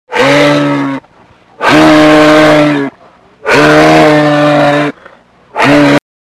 Звуки лося
Лось ревёт